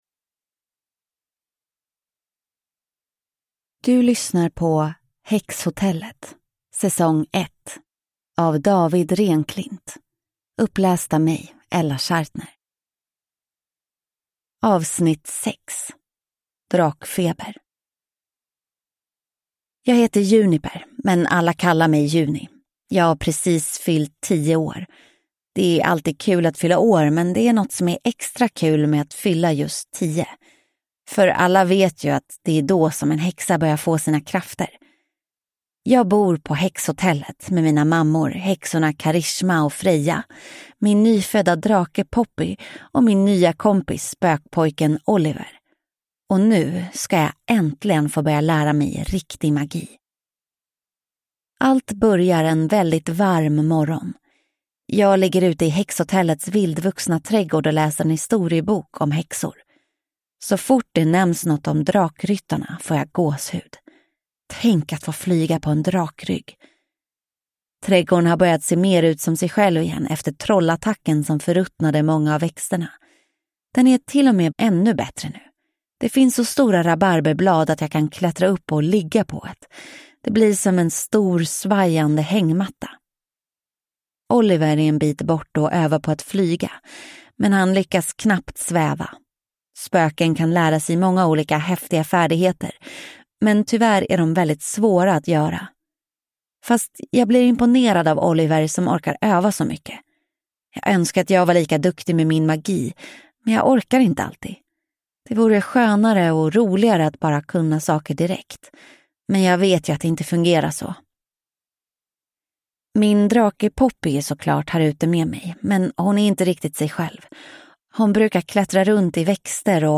Drakfeber (S1E6 Häxhotellet) – Ljudbok